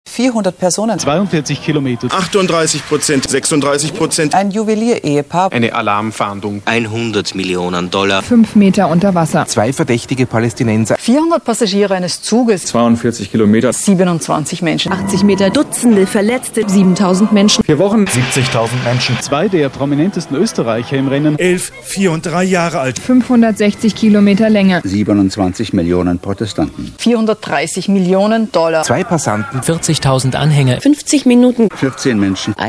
T.A.P.I.N - Collection permanente de poésie sonore et visuelle contemporaine
Les deux poèmes présentés appartiennent à un ensemble de poésie digitale, digitale dichtung, créés à partir de samples de discours volés sur la radio autrichienne, la télé ou sur les réseaux de p2p.